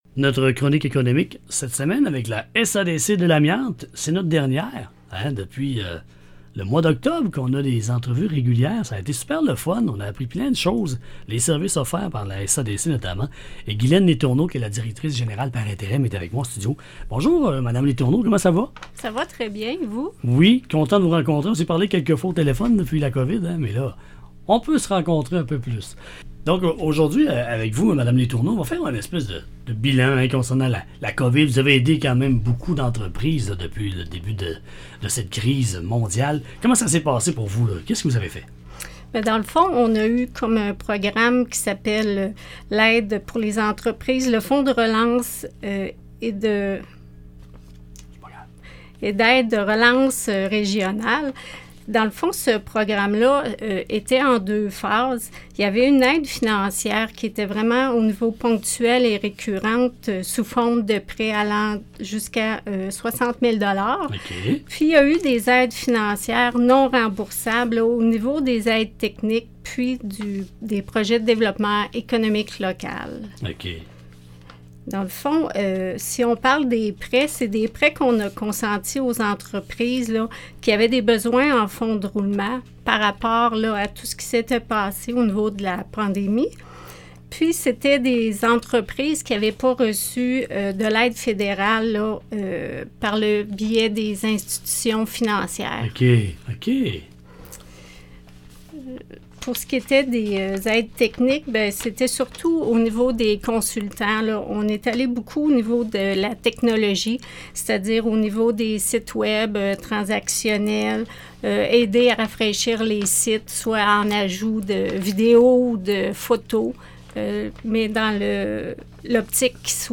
Ce sont 12 chroniques à saveur économique qui ont été diffusées sur les ondes de la station de radio locale :
12-ENTREVUE-SADC-Bilan-FARR-1.mp3